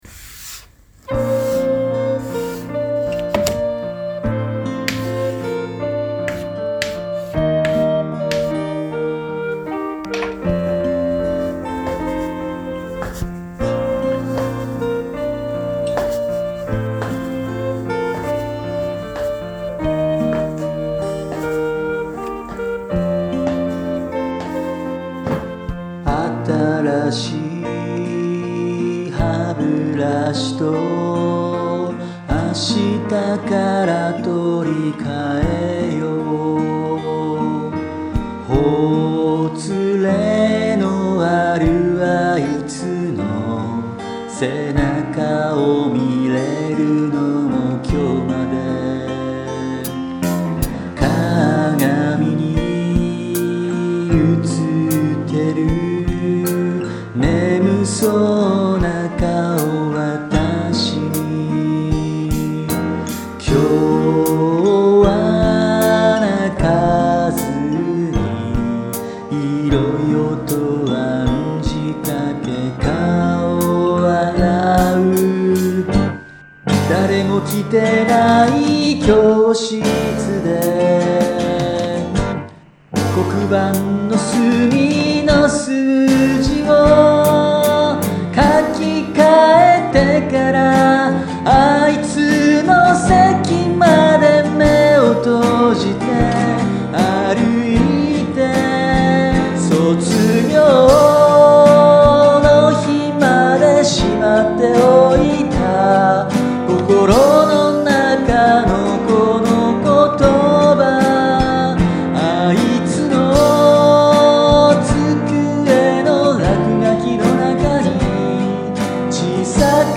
Ａギター・Ｅピアノ・Ｅベース・リコーダー・グロッケン・キーボード・ＤＴＭ・歌